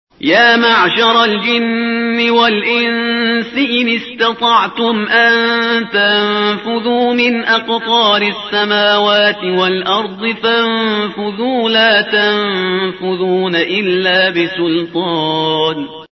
خواص و تفسیر المیزان آیه ۳۳ سوره الرحمن | منظور از سلطان در آیه ۳۳ + صوت تندخوانی
آیه ۳۳ سوره الرحمن صوتی عبدالباسط